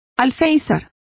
Complete with pronunciation of the translation of windowsill.